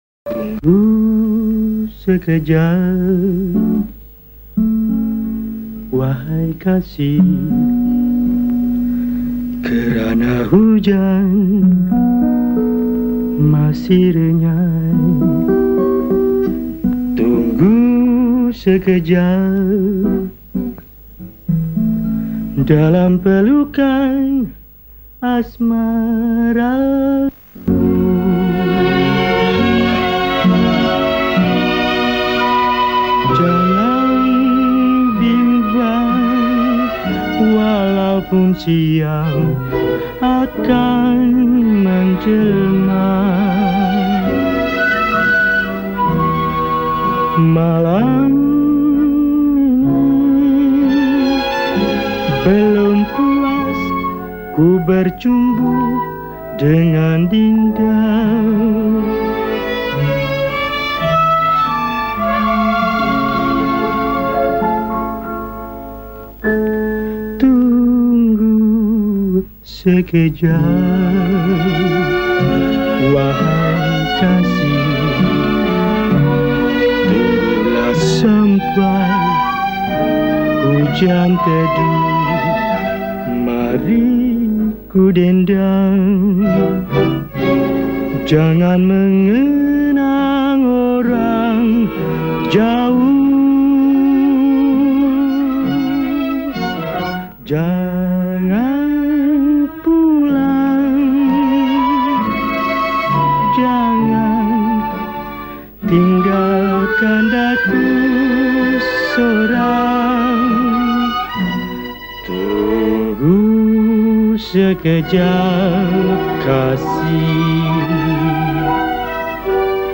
Rearranged in 2 parts harmony By
Malay Song